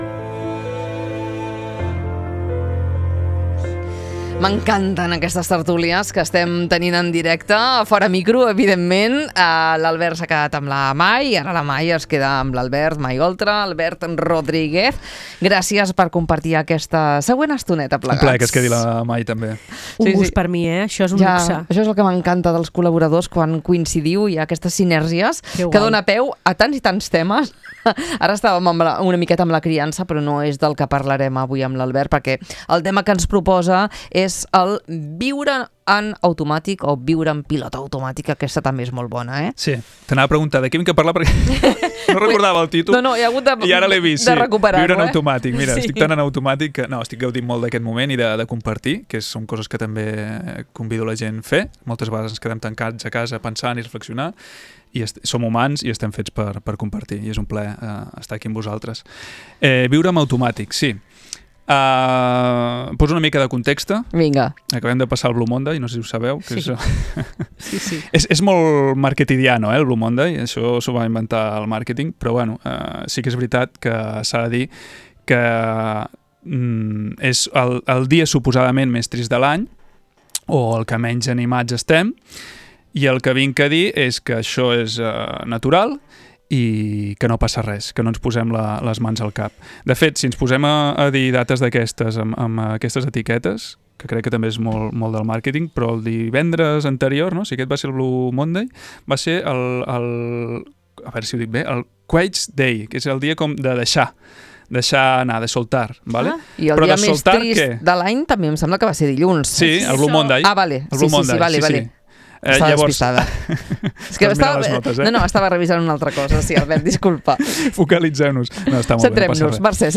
Una conversa per acabar el programa convidant a la reflexió personal.